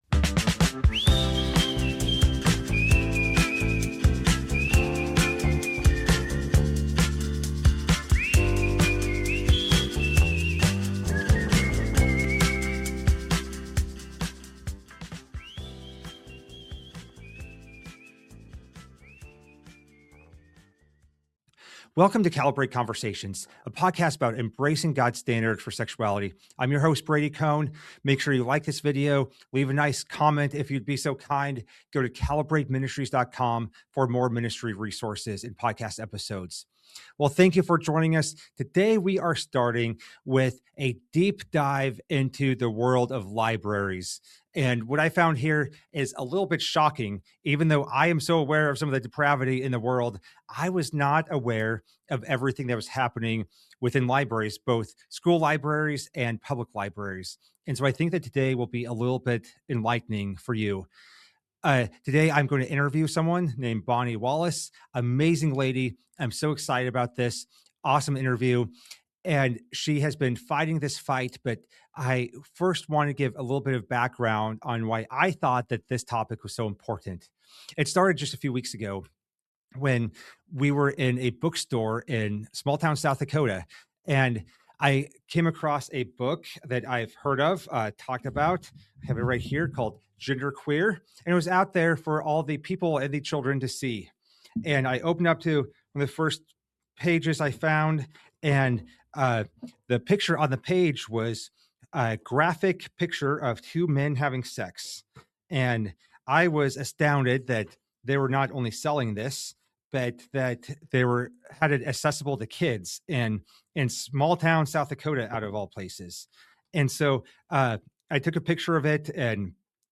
In this conversation, we break down: -How radical ideology has infiltrated the American Library Association and state library associations